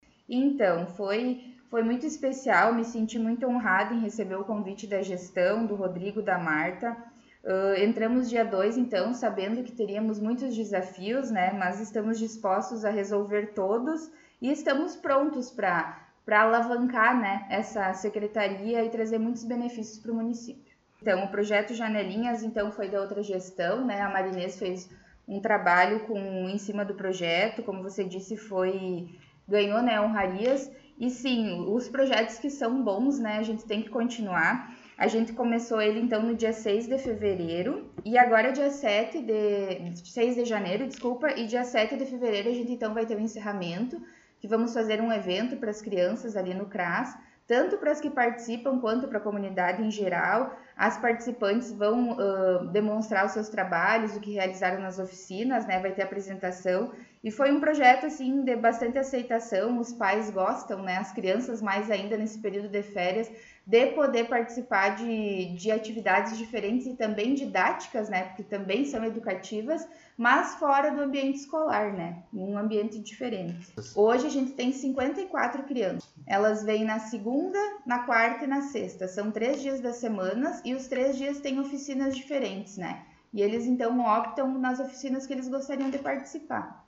Secretária de Ação Social, Habitação e Saneamento concedeu entrevista